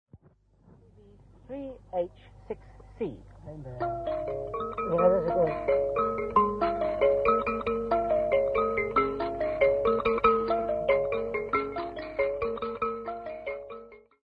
Folk Music
Field recordings
Africa South Africa Limpopo Province f-sa
sound recording-musical
Indigenous music